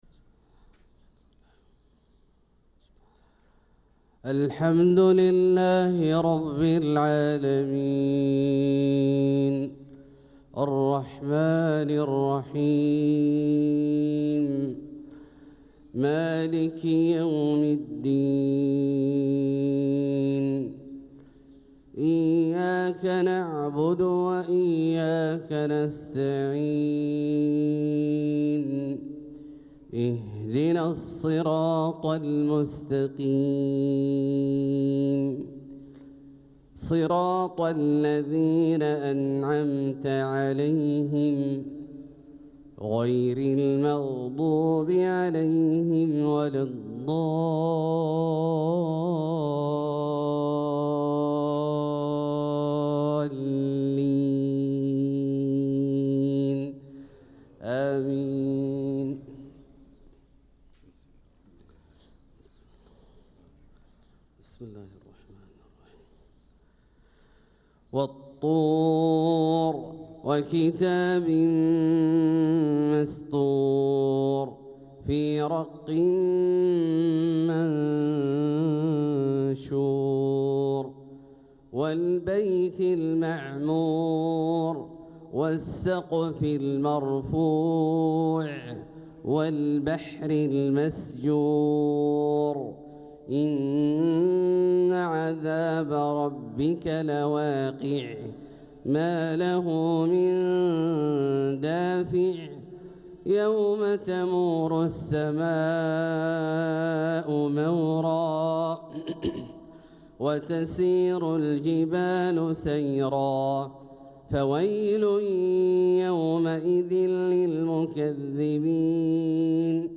صلاة الفجر للقارئ عبدالله الجهني 3 جمادي الأول 1446 هـ
تِلَاوَات الْحَرَمَيْن .